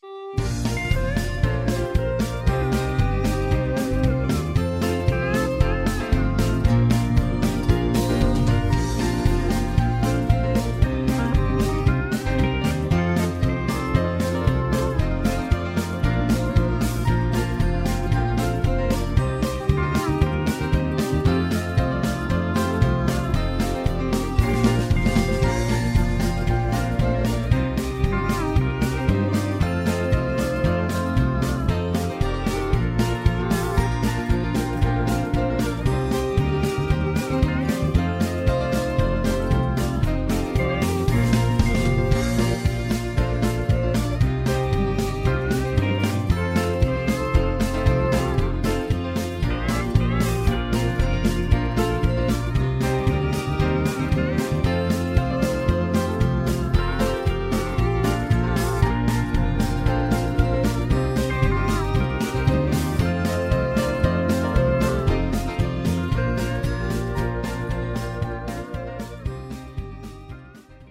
Instrumental
High Key
Singing Calls